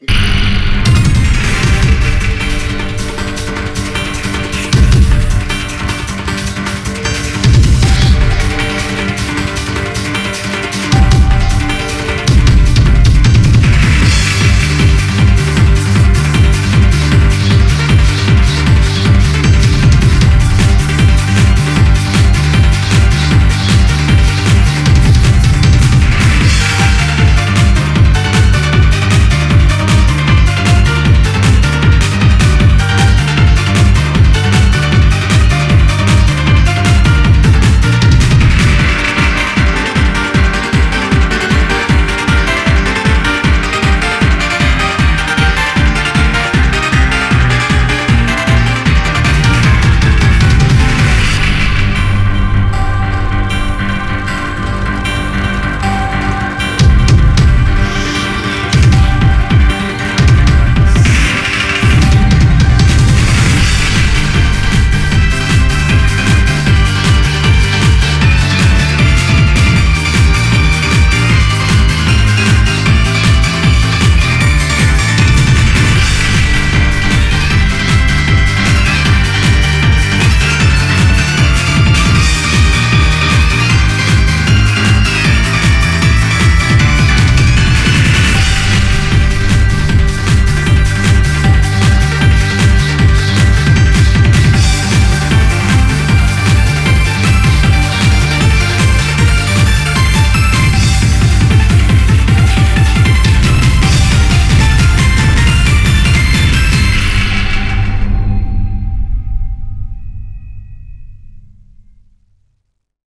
BPM78-155
Audio QualityPerfect (High Quality)
コメント[PROGRESSIVE TRIBAL]